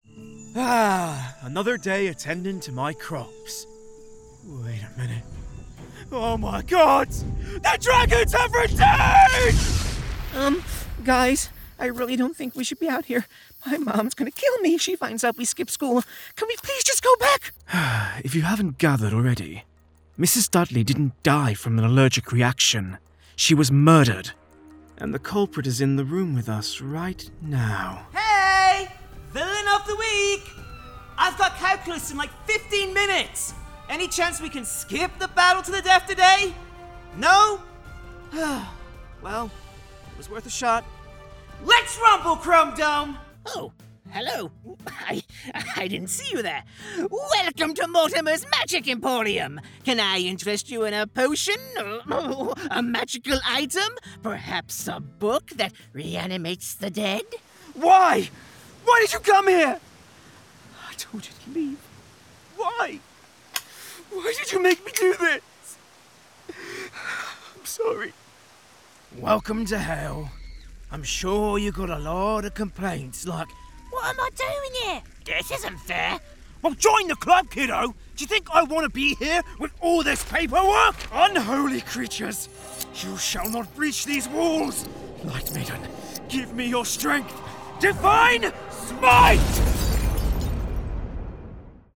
Animation Demo